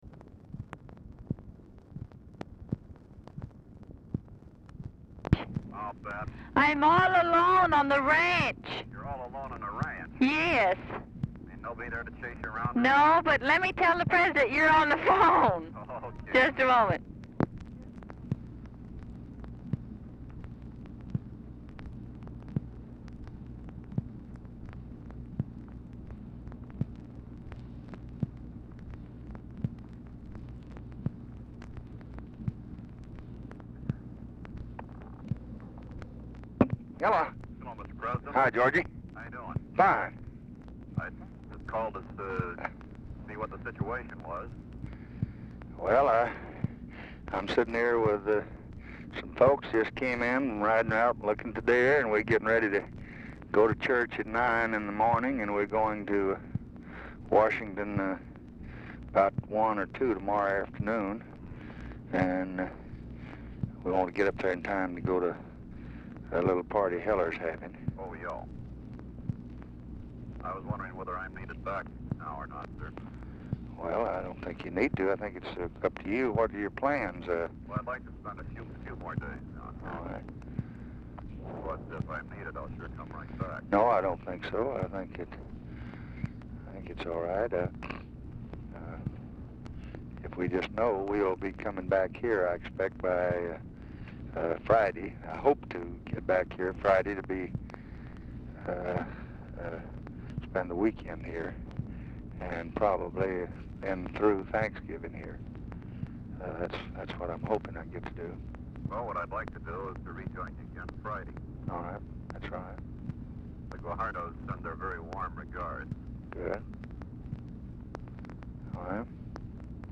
Telephone conversation
Dictation belt
LBJ Ranch, near Stonewall, Texas